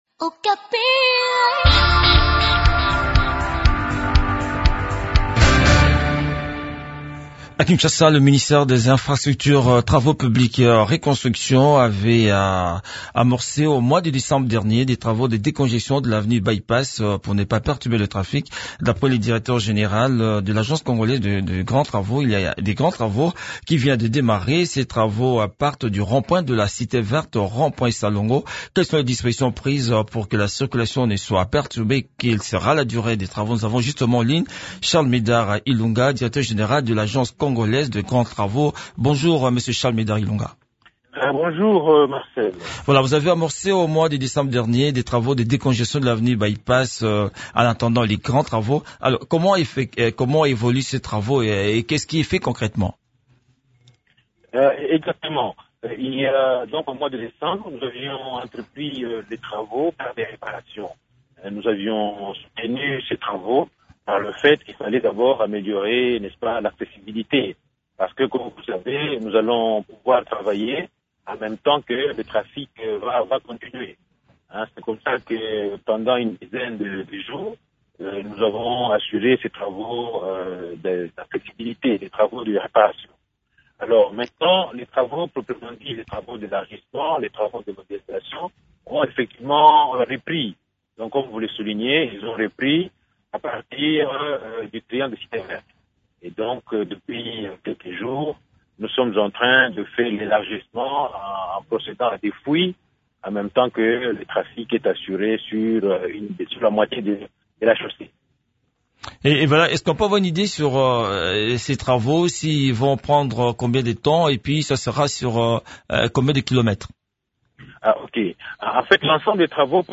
Le point sur le déroulement de ces travaux dans cet entretien